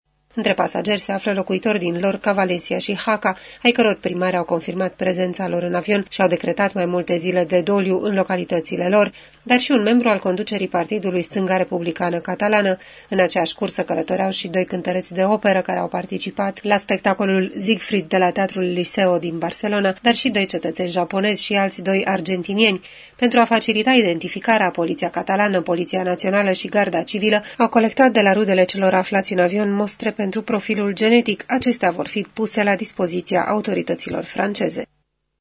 În Spania au fost decretate 3 zile de doliu, după cum transmite corespondentul RRA .